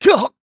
Clause_ice-Vox_Damage_kr_01_b.wav